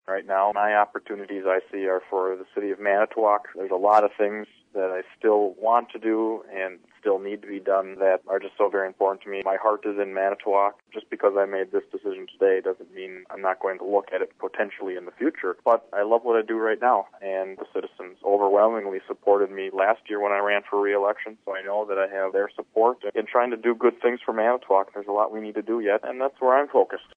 AUDIO: Manitowoc Mayor Justin Nickles tells WOMT he's made a decision regarding entering race for 6th CD.